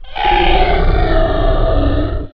snarl.wav